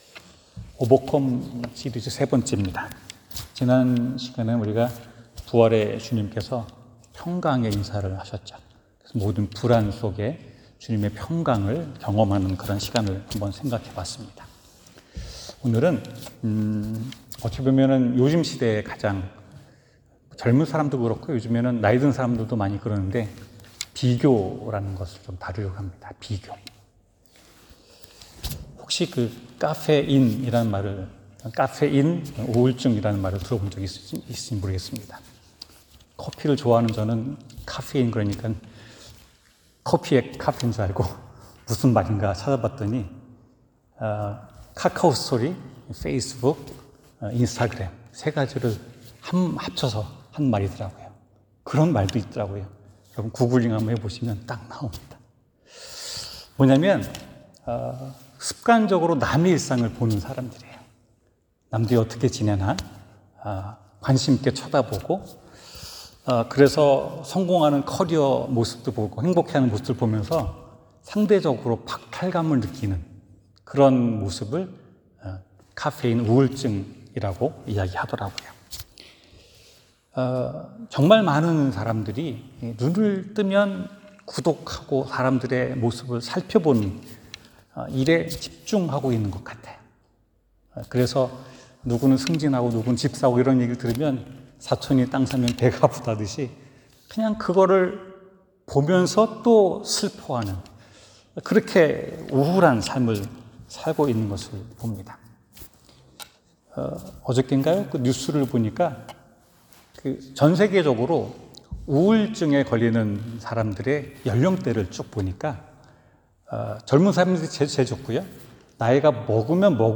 비교의 지옥에서 탈출하자 성경: 요한복음 21:15-22 설교